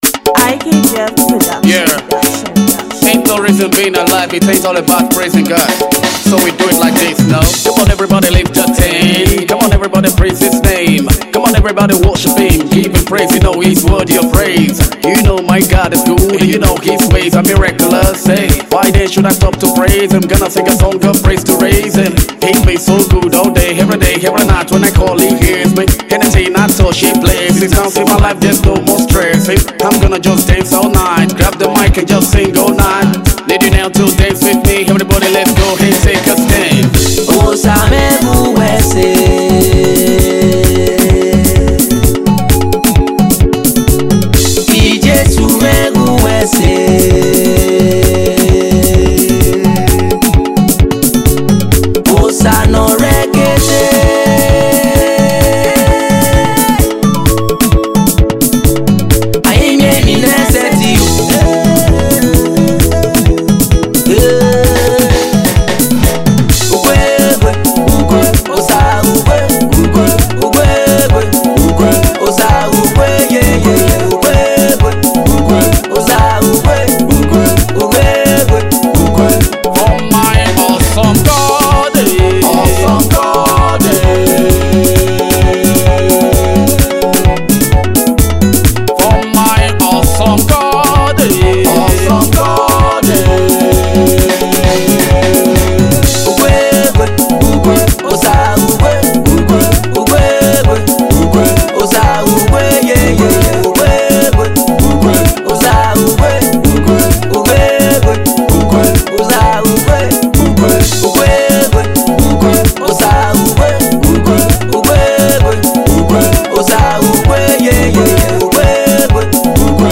I play hip hop, Afro, dance hall and worship (all Gospels).